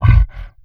MONSTER_Grunt_Breath_03_mono.wav